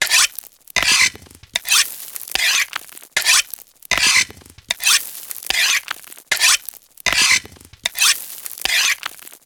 sharpen.ogg